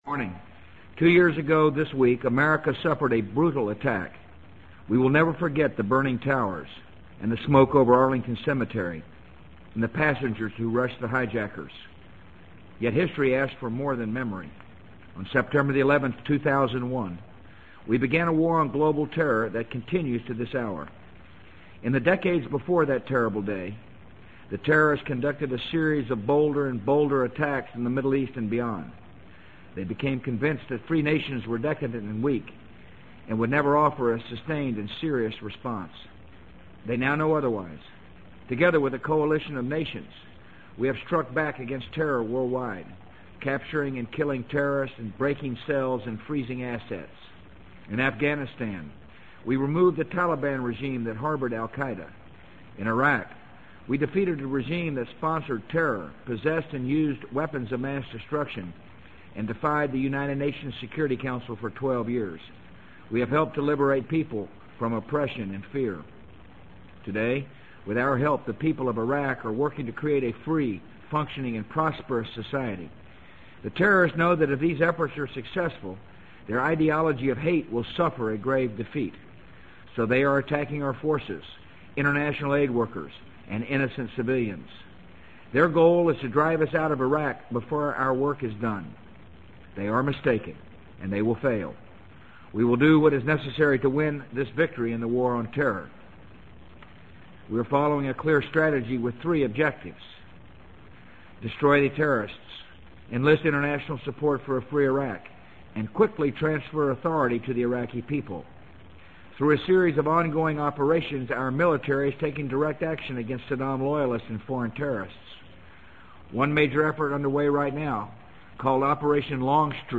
【美国总统George W. Bush电台演讲】2003-09-13 听力文件下载—在线英语听力室